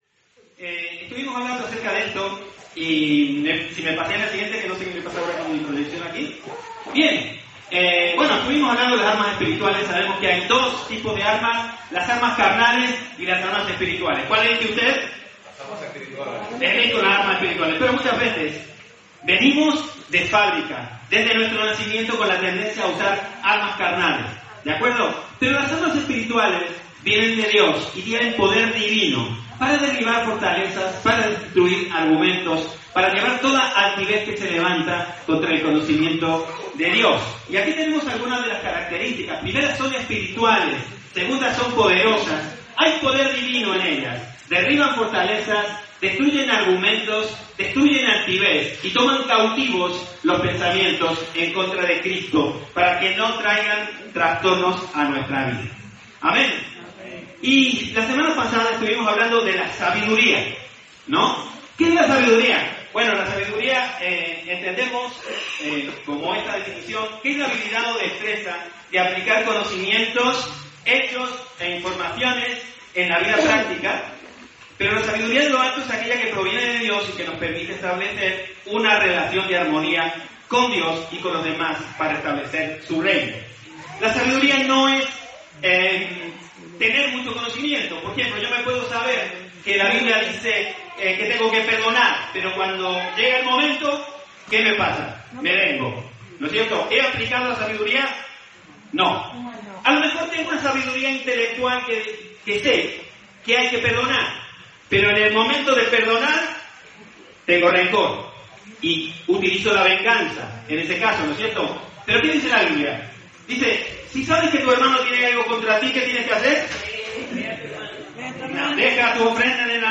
Predicaciones
Aquí compartimos los mensajes grabados cada domingo en las reuniones generales de nuestra Iglesia, predicados por los pastores de la congregación y también por hermanos invitados.